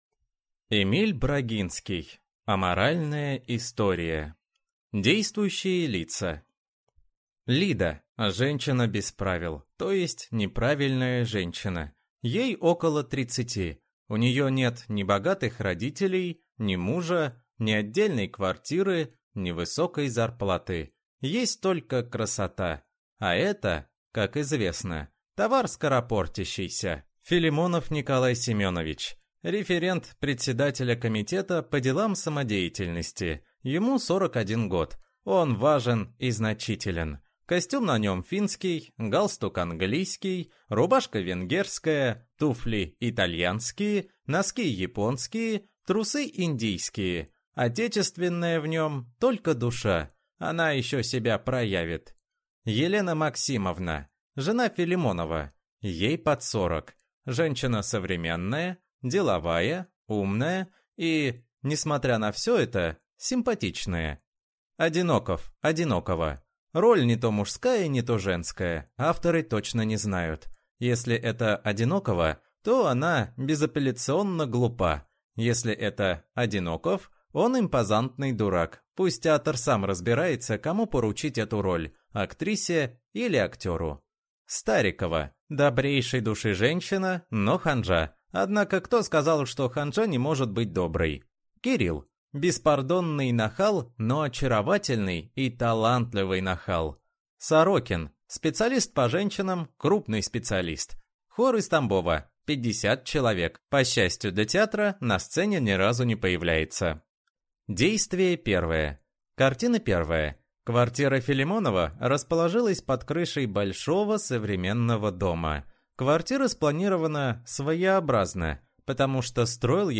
Аудиокнига Аморальная история | Библиотека аудиокниг